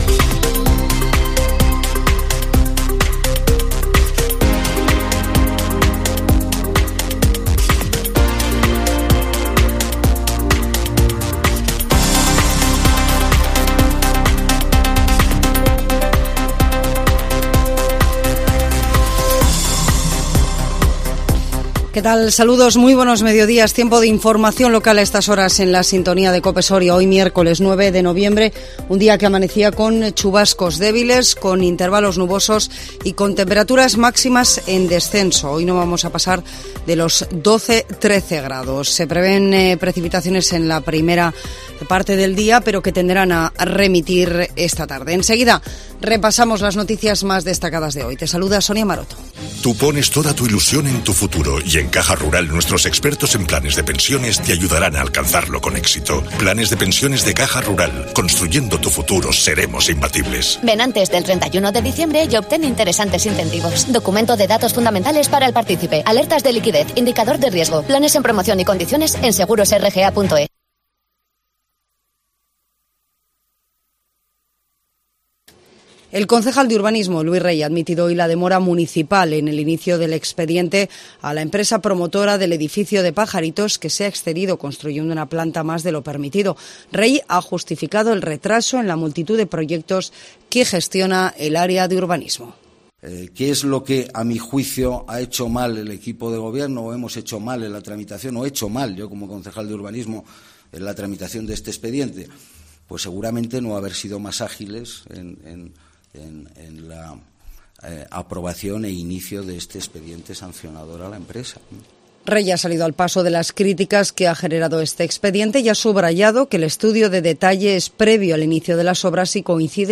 INFORMATIVO MEDIODÍA COPE SORIA 9 NOVIEMBRE 2022